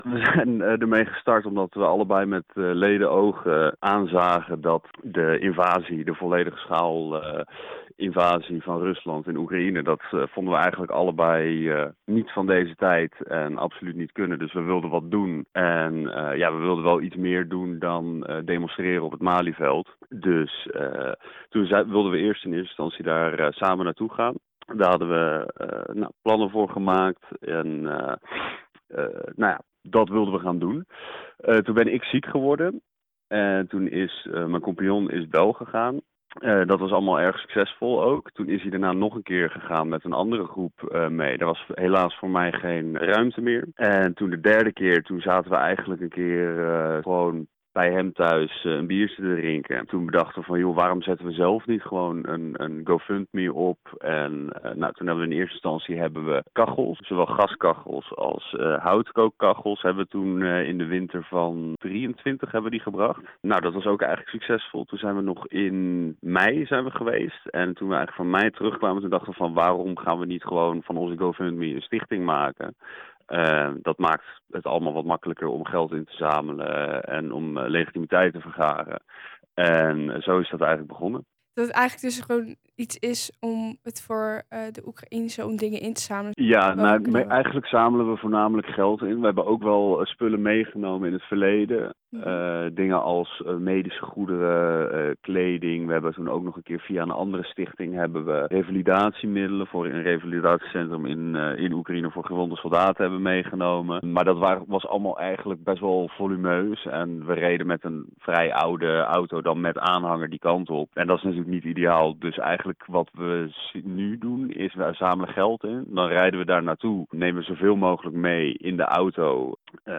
Verslaggever
in gesprek